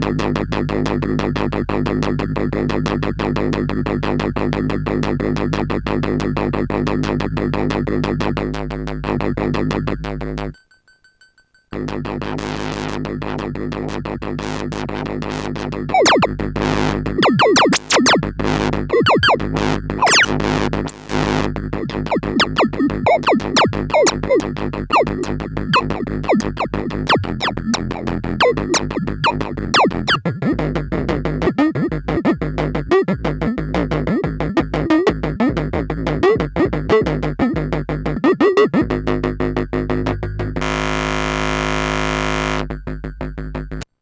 Arp Odyssey
bouncy bouncy